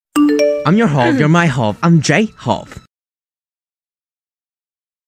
BTS Notification Sounds I'm sound effects free download